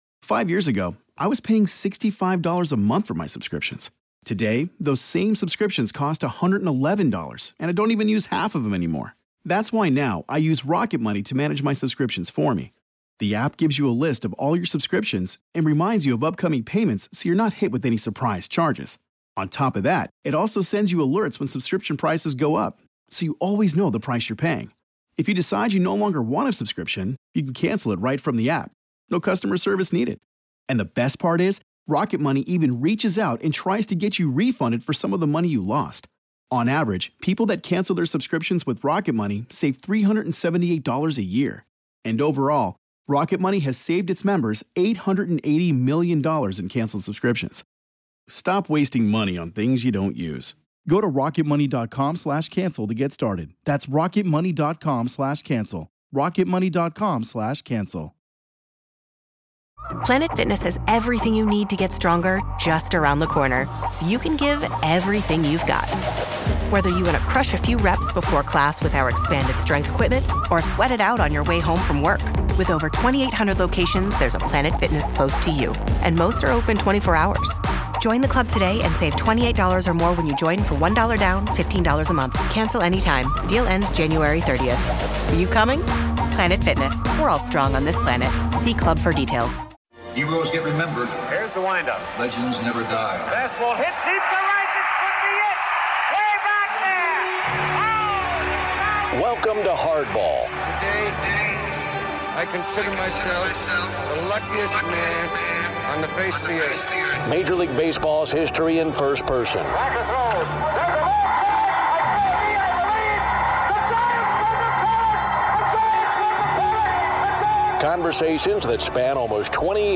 Here is my conversation with Willie...2001...on the 50th Anniversary of his debut in the Major Leagues.